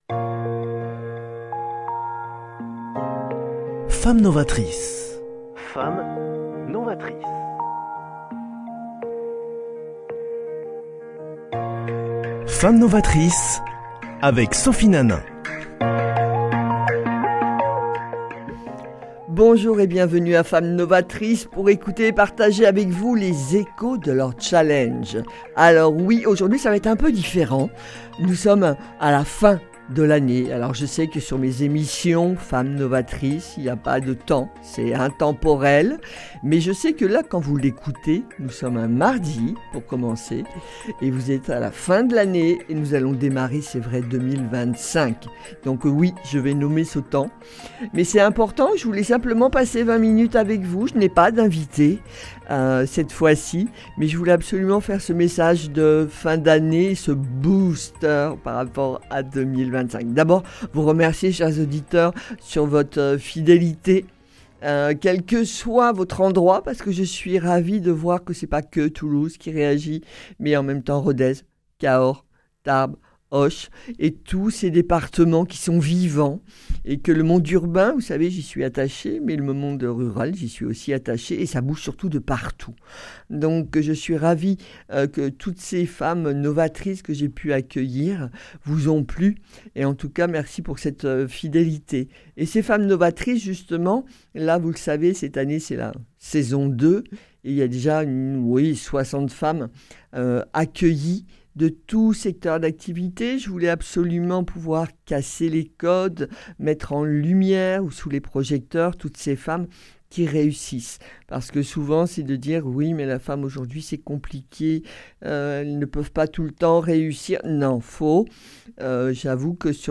Mon émission pour terminer l’année C’est moi qui vous parle, qui vous transmet un message à vous tous ! Des pensées, des réflexions positives sur votre bilan de 2024 et vos intentions pour 2025